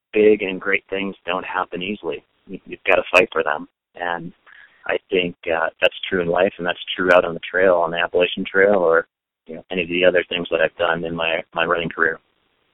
JUREK SAYS FIGHTING FOR BIG THINGS IS WHAT HE DOES.